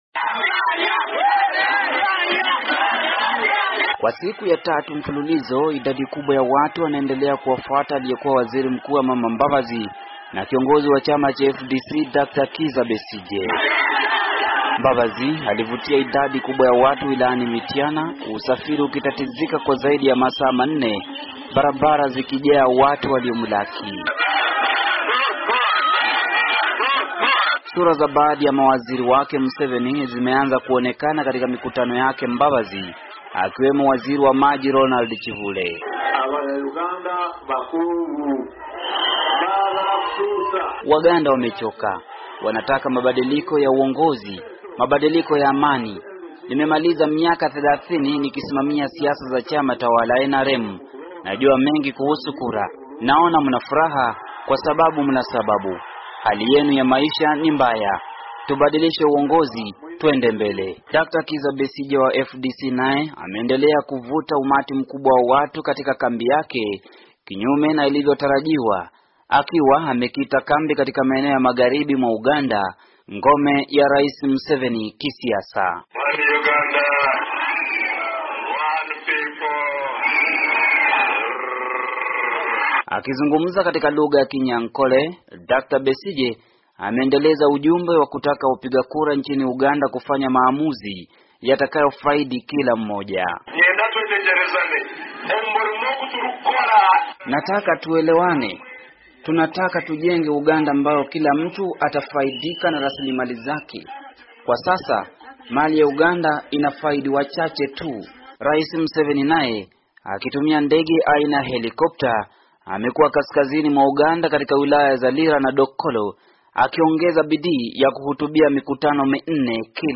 Ripoti ya Mwandishi